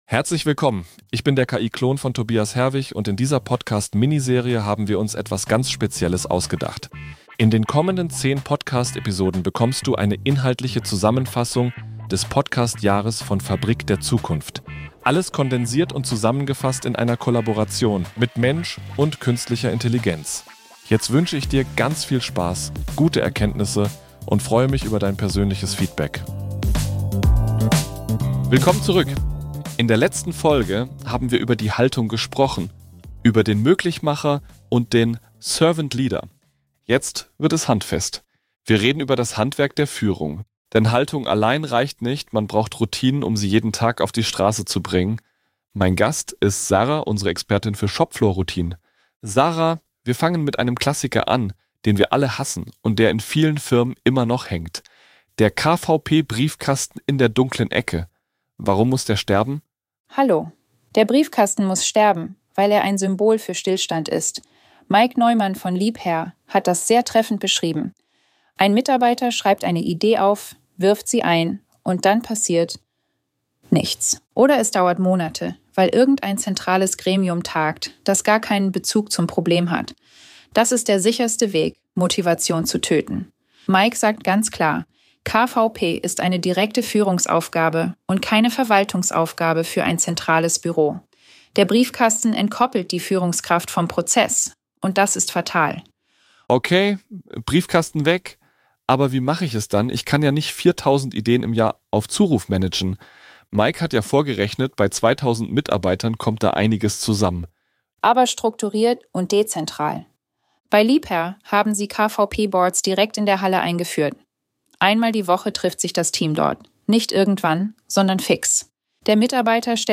spricht der KI-Klon